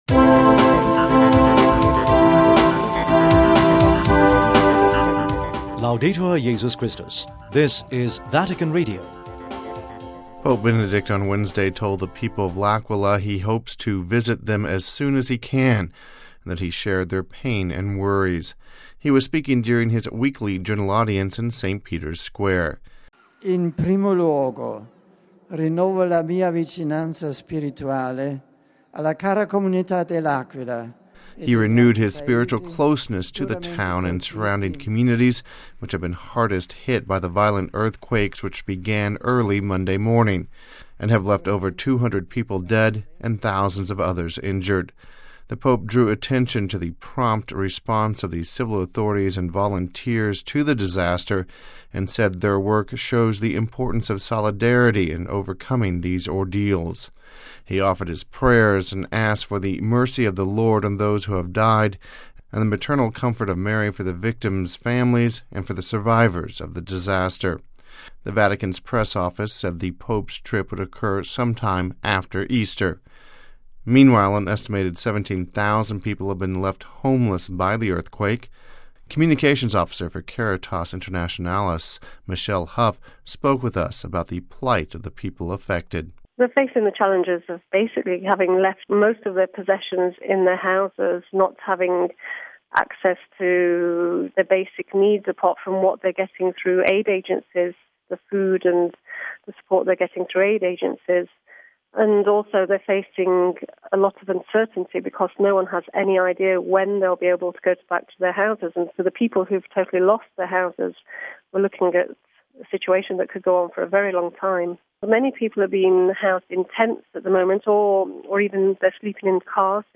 (08 Apr 09 - RV) Pope Benedict XVI said during his general audience on Wednesday that he will soon visit L'Aquila, the centre of the devastation in Italy after Monday's earthquake. We have this report...